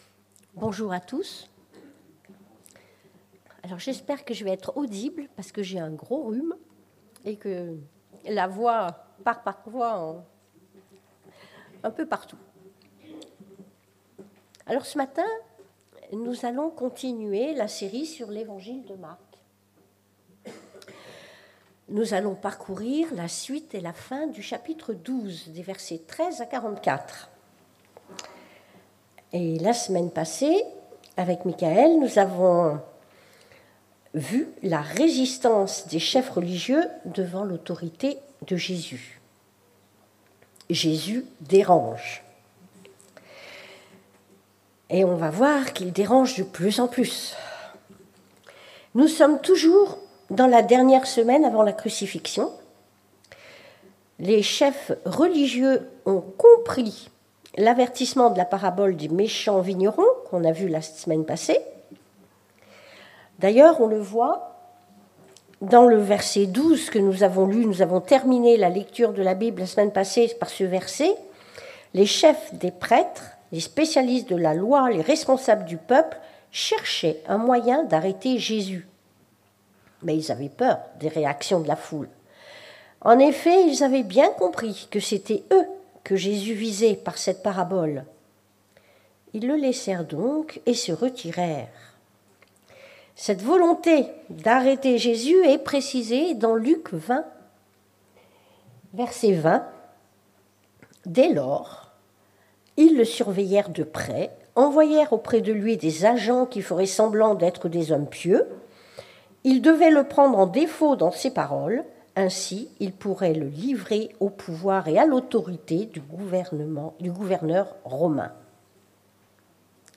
Dépassons nos préoccupations, pour l'essentiel - Prédication de l'Eglise Protestante Evangélique de Crest sur l'Evangile de Marc